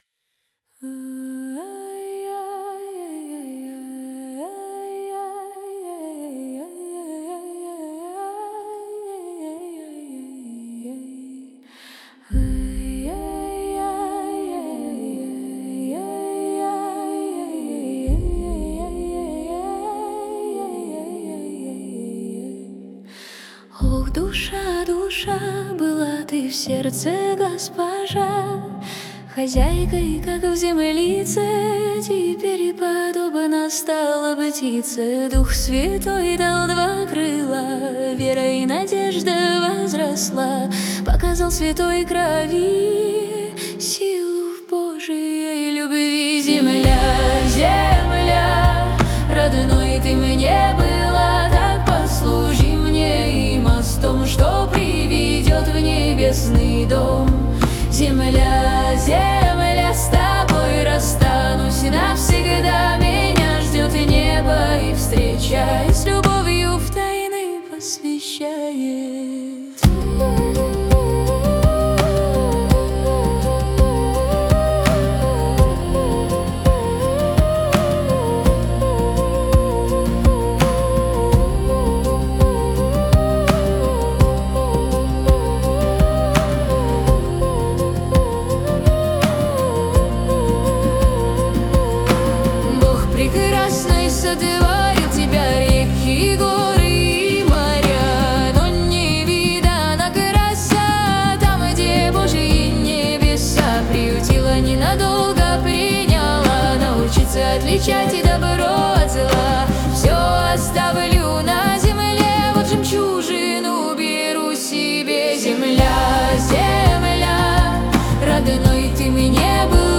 песня ai
15 просмотров 35 прослушиваний 3 скачивания BPM: 83